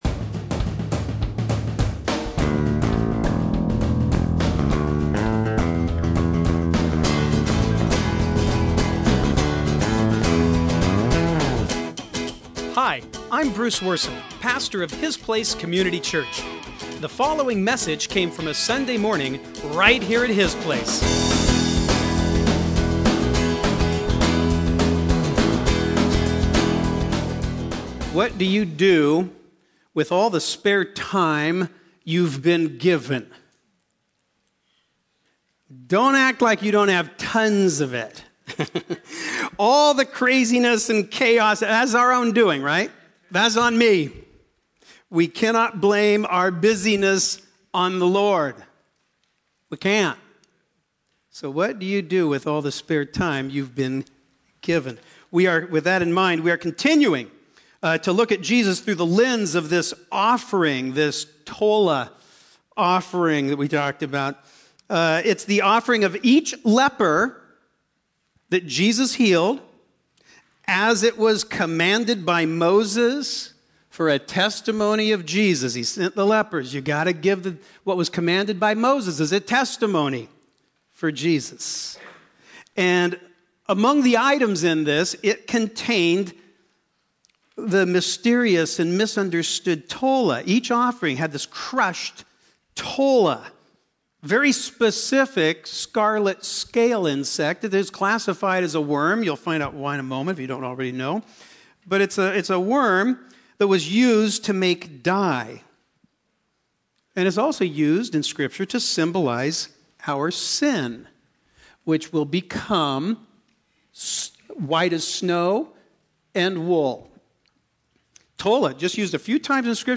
Sunday morning messages from His Place Community Church in Burlington, Washington. These surprisingly candid teachings incorporate a balanced mix of lighthearted self-awareness and thoughtful God-awareness.